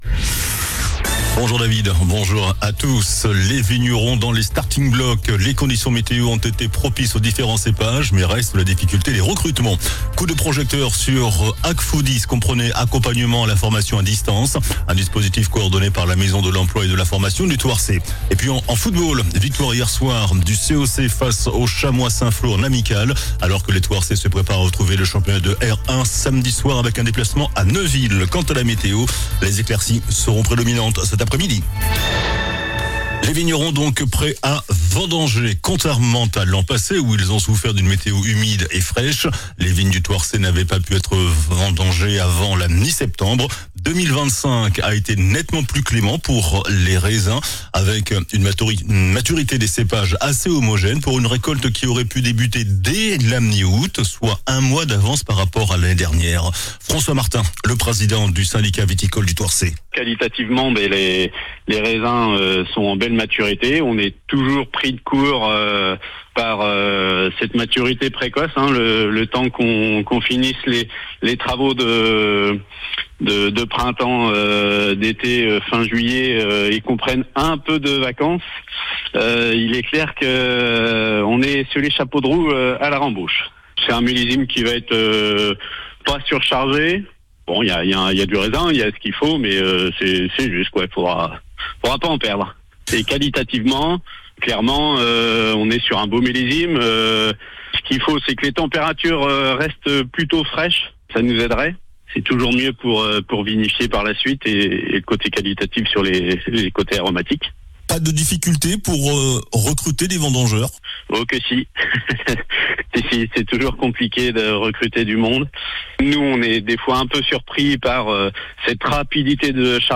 JOURNAL DU JEUDI 21 AOÛT ( MIDI )